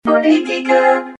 A vocoder was used on “pacifico” and then on “politica”, you can hear pre and post-treatment here.
politica-orig.mp3